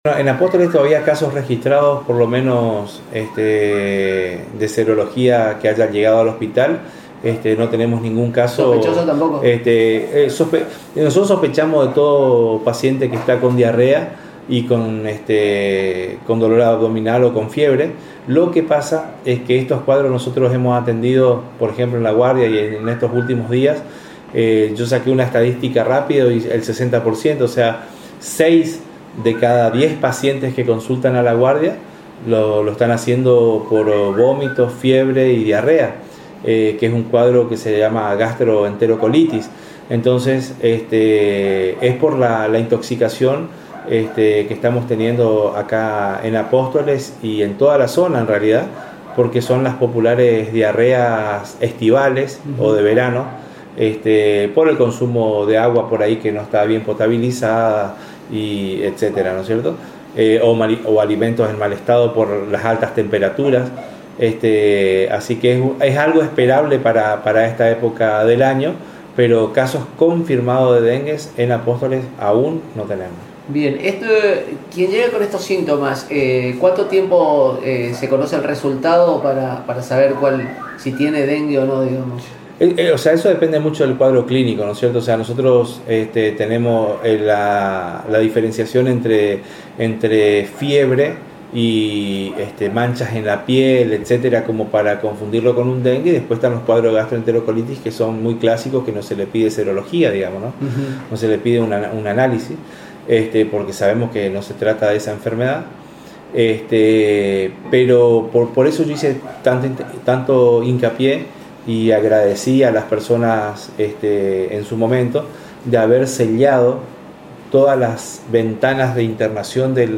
En charla exclusiva